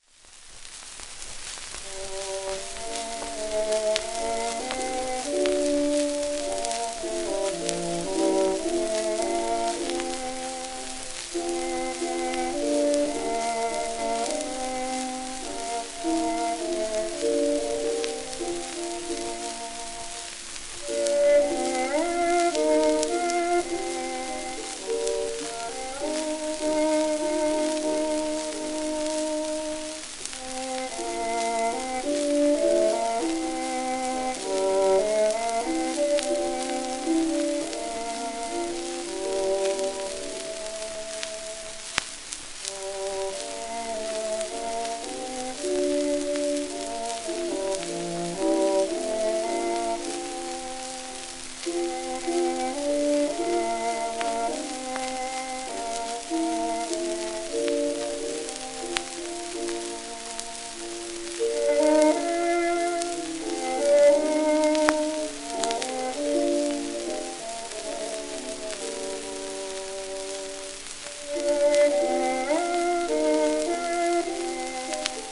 w/ピアノ
12インチ片面盤
1916年録音　80rpm
旧 旧吹込みの略、電気録音以前の機械式録音盤（ラッパ吹込み）